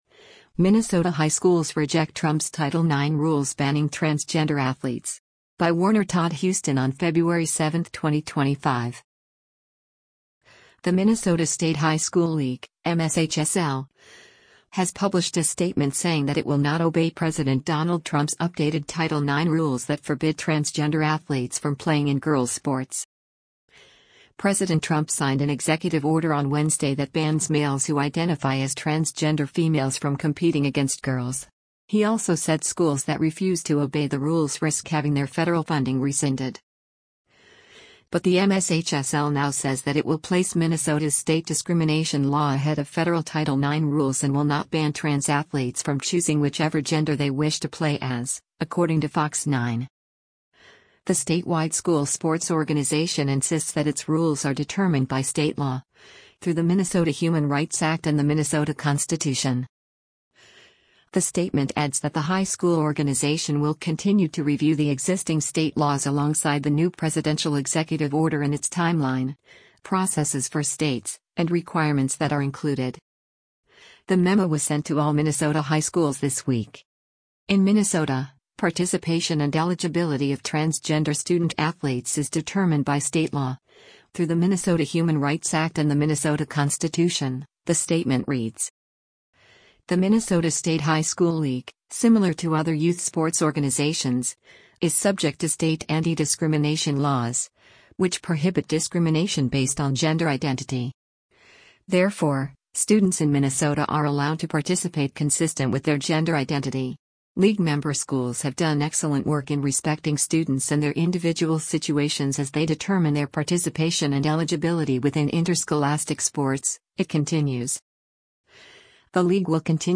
US President Donald Trump speaks after a swearing-in ceremony for Pam Bondi, US attorney g